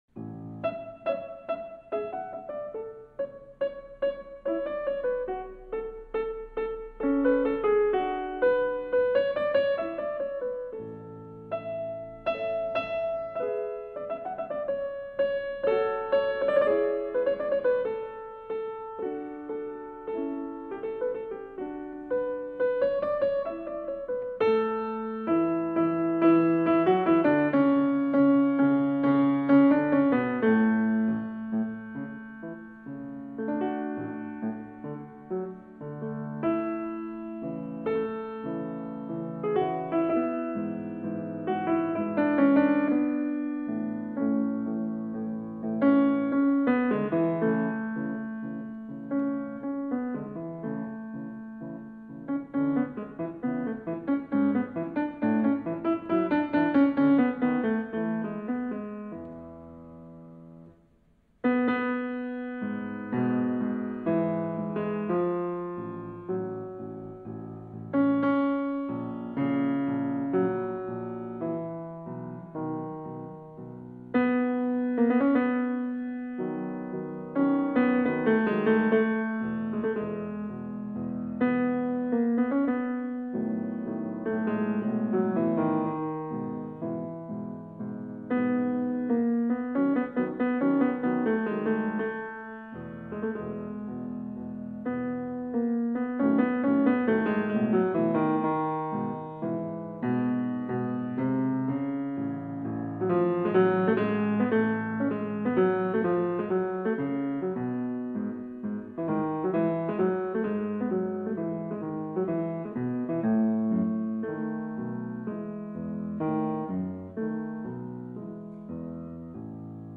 Es beginnt leise.
Es ist ein audiovisuelles Ritual.